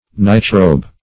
Search Result for " nightrobe" : The Collaborative International Dictionary of English v.0.48: nightrobe \night"robe`\, night-robe \night"-robe`\n. 1.
nightrobe.mp3